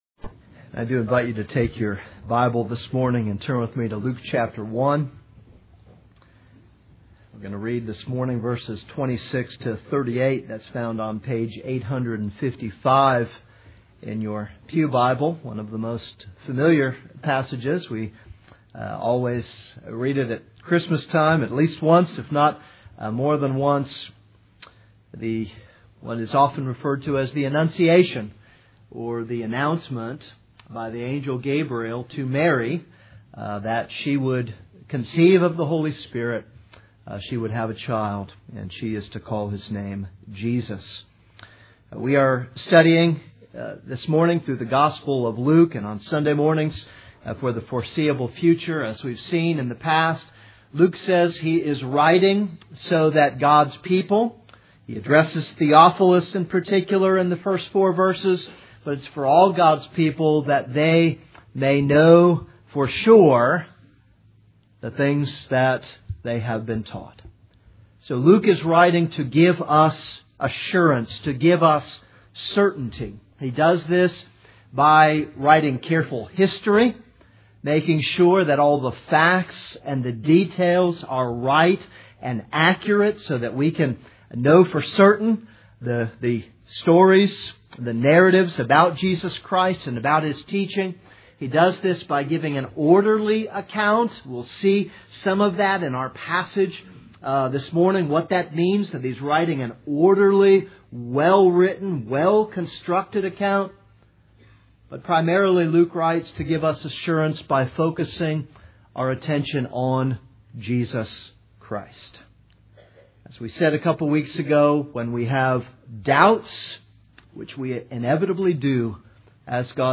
This is a sermon on.Luke 1:26-38.